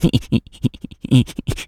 Animal_Impersonations
rabbit_squeak_04.wav